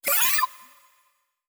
Negatvie Robot Alert.wav